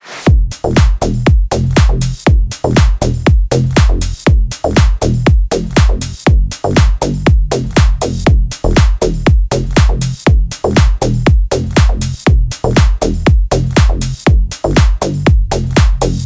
Но, к сожалению, я пыжанул, одним осцем всё же не получается сделать аналоговый модуль( Вложения cnariy mud(3).mp3 cnariy mud(3).mp3 637,8 KB · Просмотры: 2.574